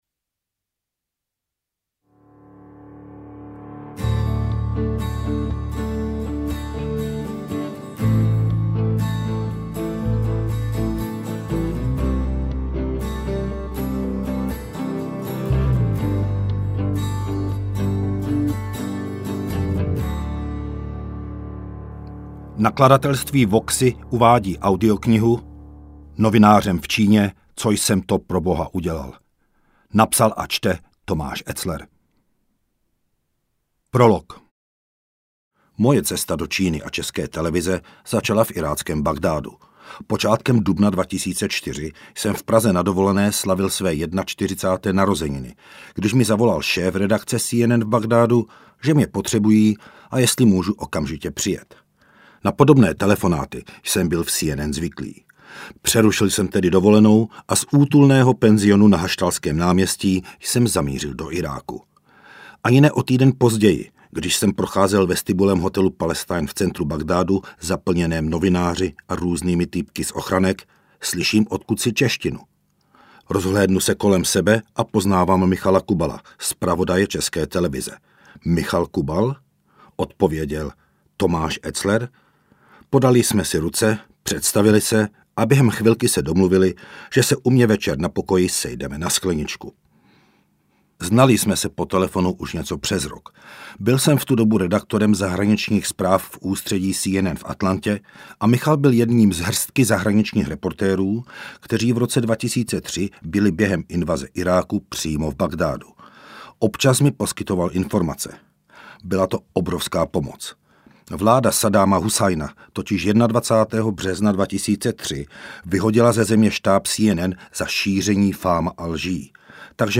Interpret:  Tomáš Etzler